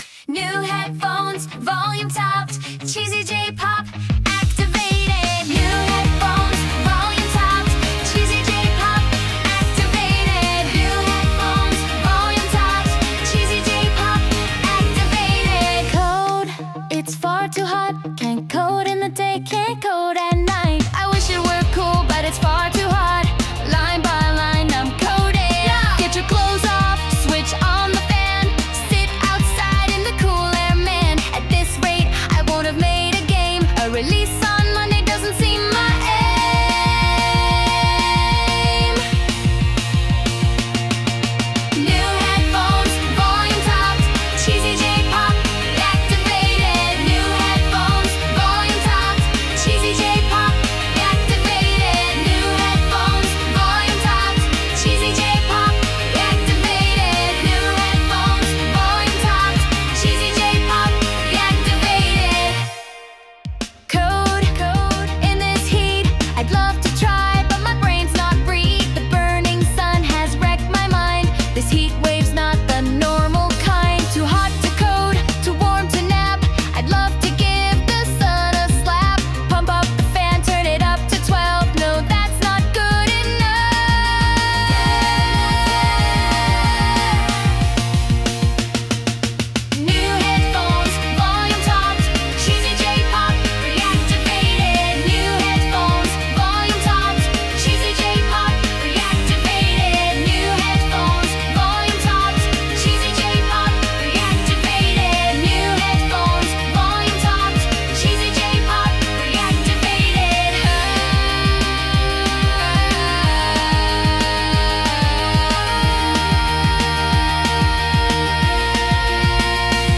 They've been on charge, and will be doing the whole "Headphones On, Volume Up, Cheesy J-Pop, Activated" thing, later on.
Lyrics : By me
Sung by Suno